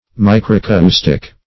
Micracoustic \Mi`cra*cous"tic\, a. Same as Microustic .